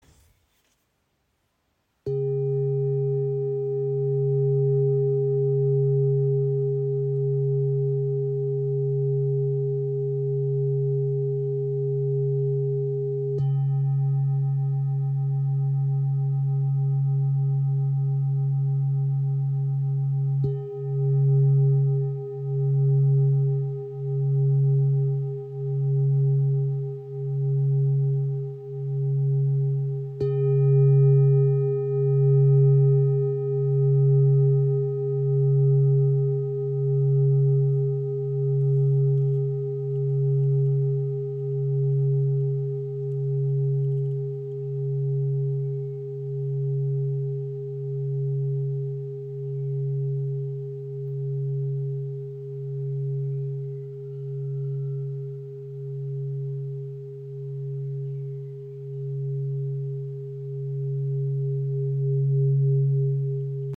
Tibetische Klangschale 24.5 cm – klarer Ton C# aus Nepal • Raven Spirit
Handgefertigte Klangschale mit Blume des Lebens und Muschelhorn. Klarer, tiefer Ton C – ideal für Klangarbeit, Meditation und Entspannung.
Klangbeispiel
Ihr obertonreicher Klang im Ton C ist klar und erdend.